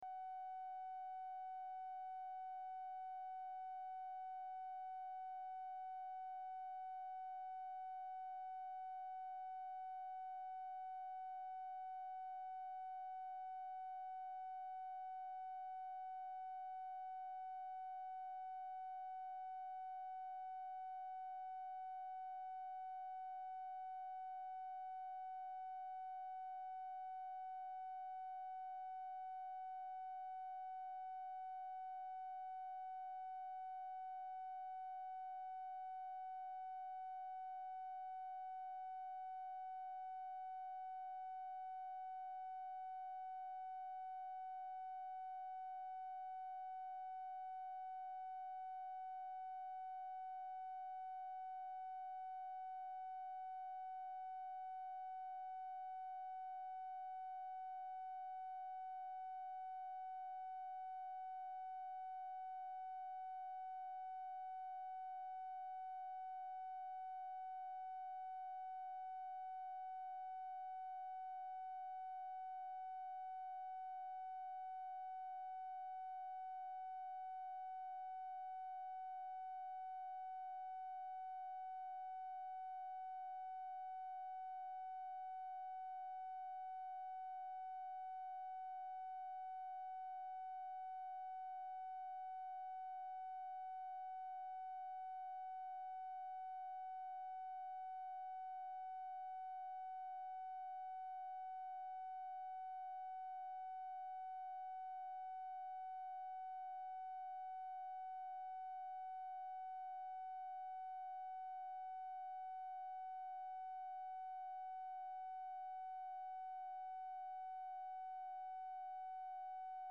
Telephone conversation # 3933, sound recording, LBJ and MCGEORGE BUNDY, 6/27/1964, 12:55PM | Discover LBJ
Format Dictation belt
Location Of Speaker 1 Oval Office or unknown location
Specific Item Type Telephone conversation Subject Defense Diplomacy Middle East Southeast Asia United Nations Western Europe